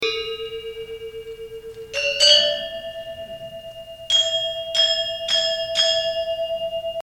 4. Listening to balinese gamelan:
example to hear the beats produced when a pair of gender instruments play the same part together.
Beats.mp3